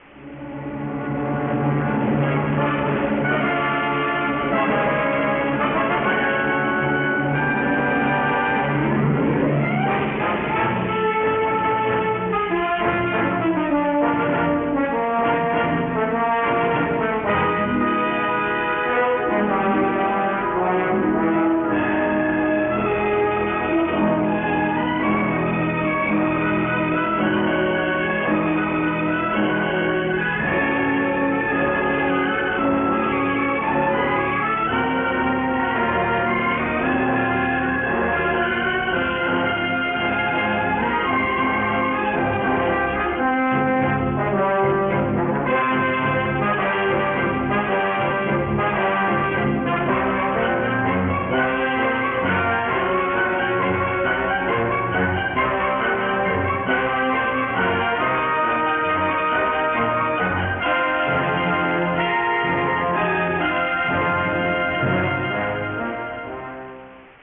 (musiche di repertorio)
Original Track Music